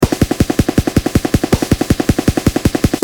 Drumroll
Drumroll.mp3